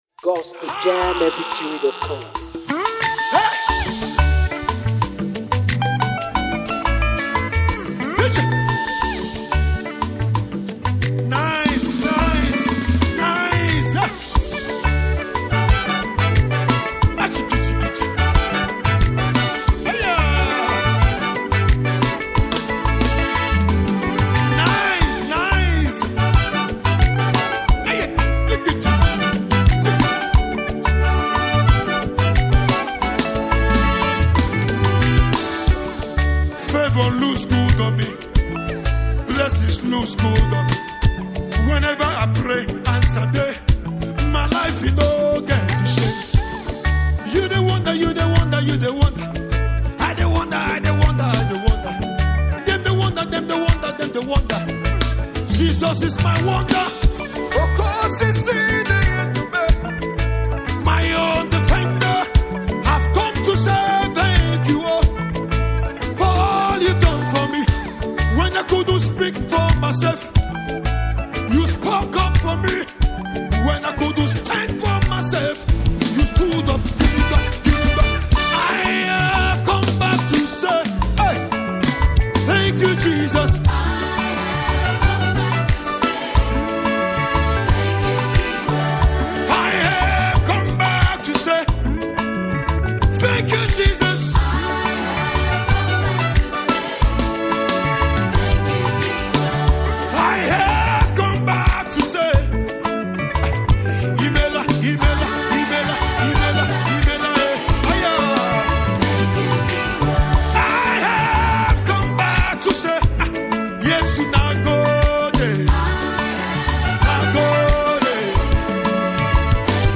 heartfelt gospel song
Filled with joyful melodies and powerful vocals